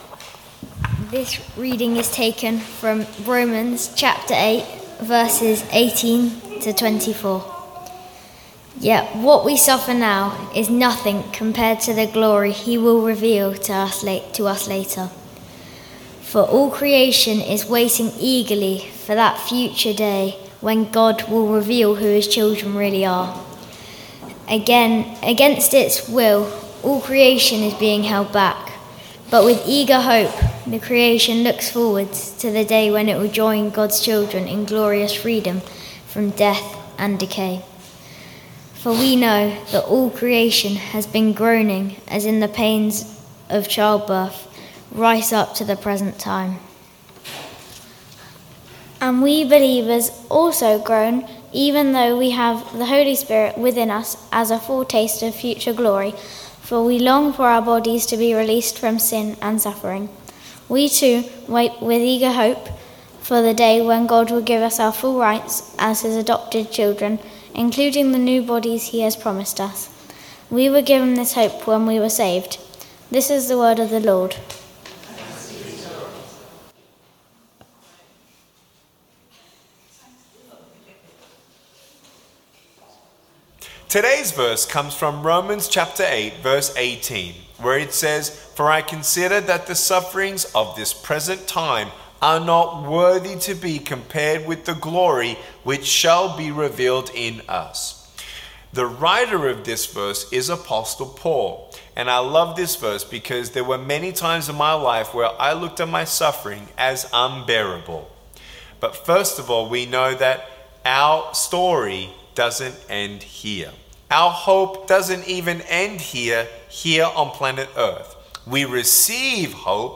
Sermon 23rd July 2023 11am gathering
We have recorded our talk in case you missed it or want to listen again.